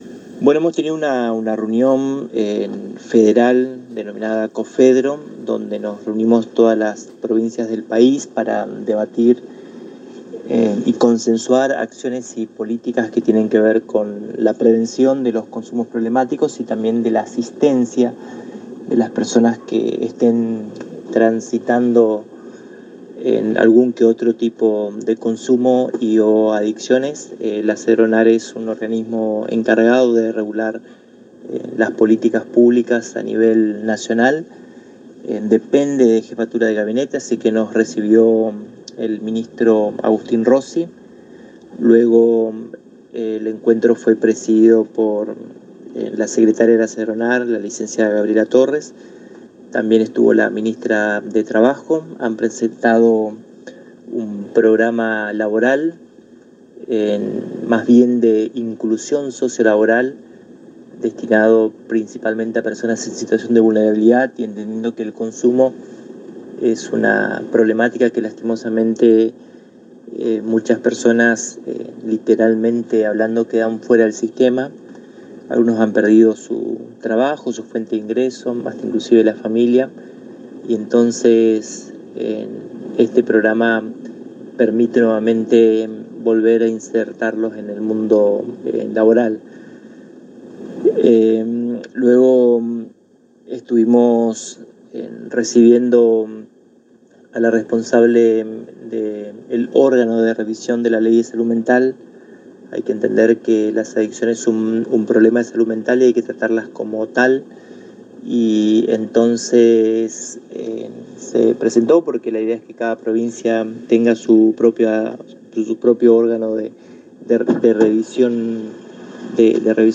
El ministro de Prevención de Adicciones y Control de Drogas, Mgter. Samuel López en diálogo exclusivo con la ANG manifestó que los ejes de trabajo giraron en torno a política de inclusión socio laboral a partir de la puesta en marcha del Programa Fomentar Empleo entre el Ministerio de Trabajo, Empleo y Seguridad Social y el Sedronar de la Nación.